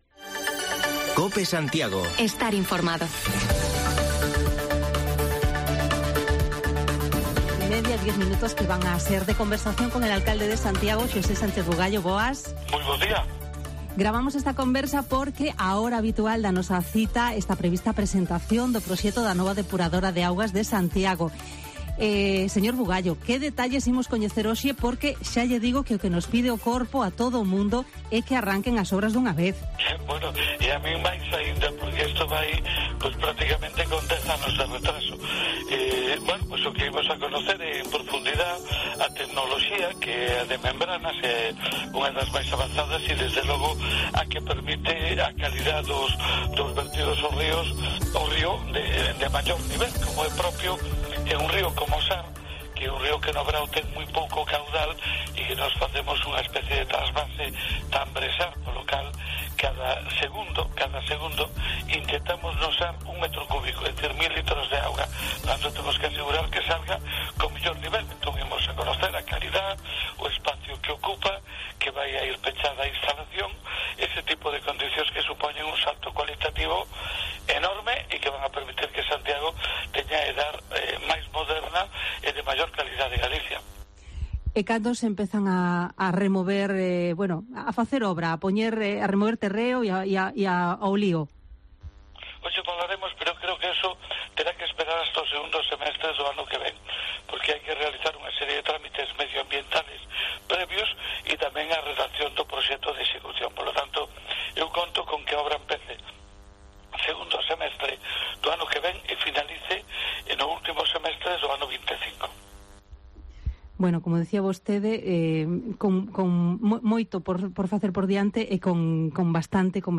Repasamos la actualidad local con el alcalde de Santiago, Sánchez Bugallo, que no descarta que a la vista del incremento de casos de covid haya que revisar el aforo de algunas celebraciones programadas para las fiestas del Apóstol.